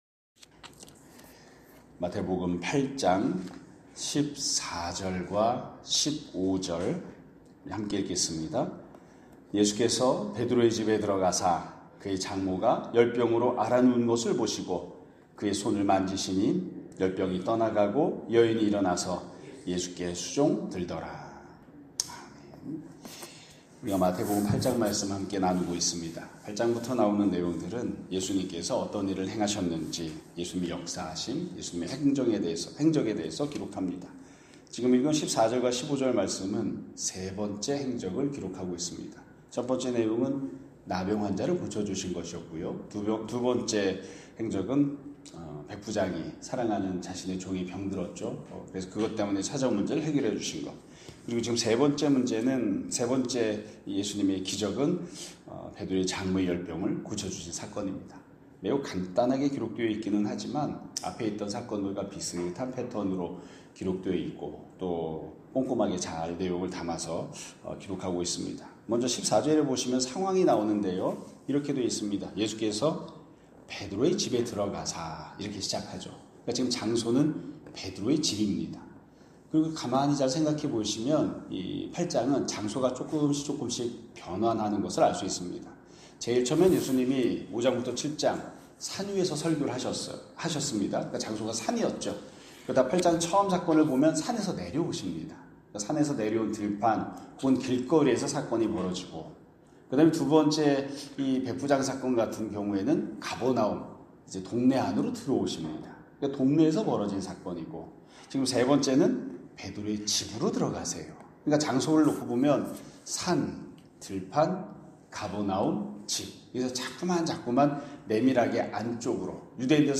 2025년 7월 10일(목요일) <아침예배> 설교입니다.